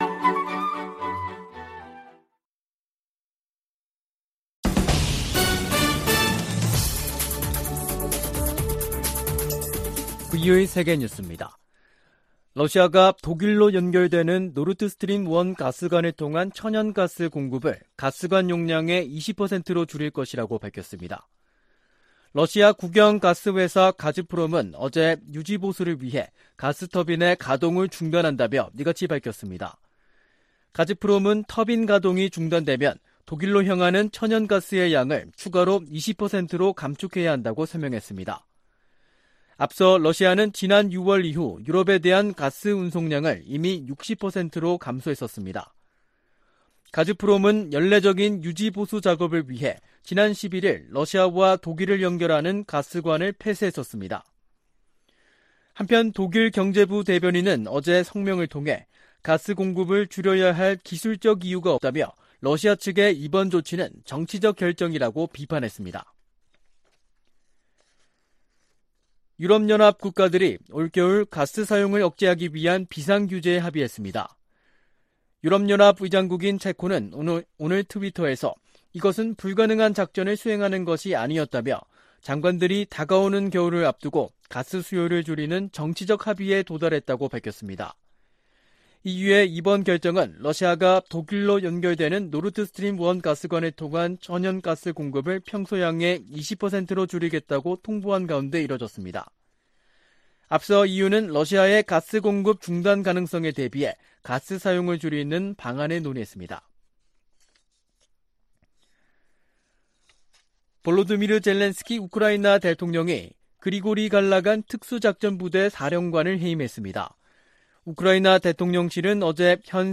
VOA 한국어 간판 뉴스 프로그램 '뉴스 투데이', 2022년 7월 26일 2부 방송입니다. 미 국무부는 모든 가용한 수단을 동원해 북한 악의적 사이버 공격 세력을 추적하고 있다고 밝혔습니다. 아미 베라 미 하원의원은 북한의 핵실험을 한일 갈등 극복과 미한일 관계 강화 계기로 삼아야 한다고 말했습니다. 미군과 한국 군이 세계 최강 공격헬기를 동원한 훈련을 실시했습니다.